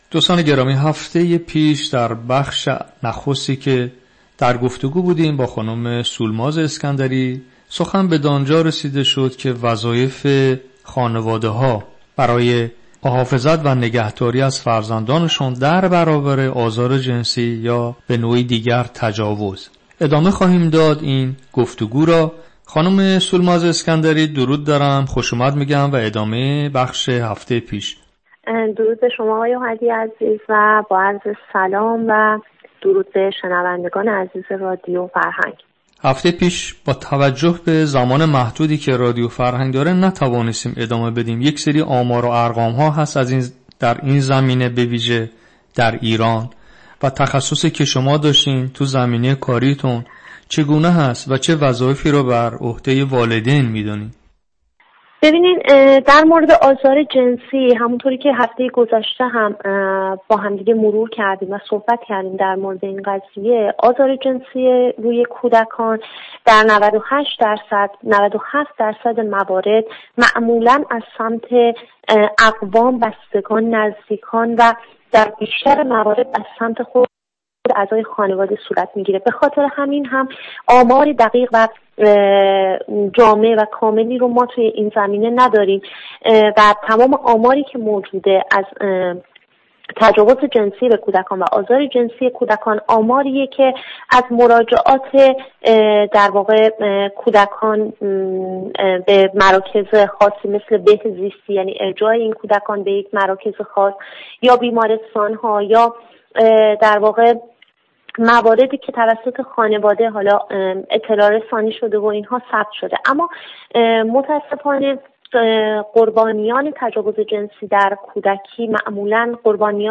گفت و شنود رادیو فرهنگ